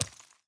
footsteps
ore-05.ogg